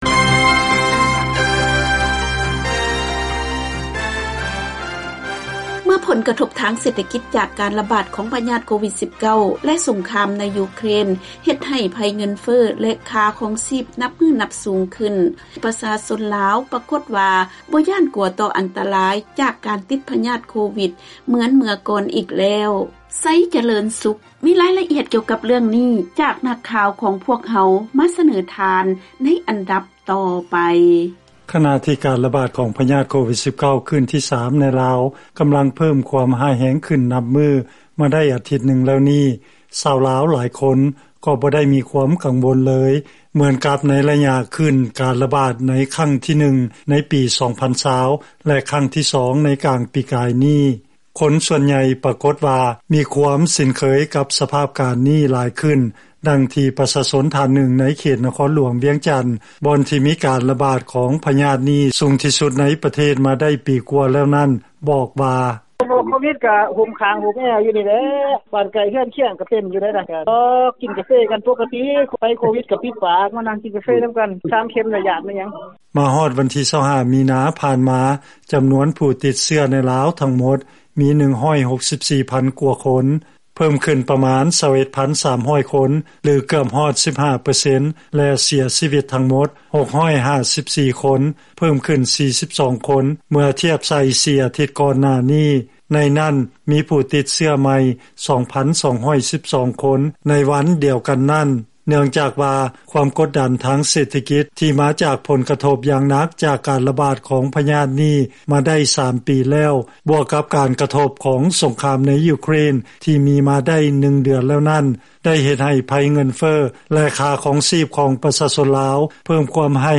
ເຊີນຟັງລາຍງານ ຄົນລາວສ່ວນໃຫຍ່ ເຫັນວ່າ ອັນຕະລາຍຂອງໂຄວິດ ອາດຈະບໍ່ເທົ່າກັບ ຄວາມທຸກຍາກ ທີ່ເກີດຈາກມາດຕະການປິດເມືອງ ແລະສົງຄາມໃນຢູເຄຣນ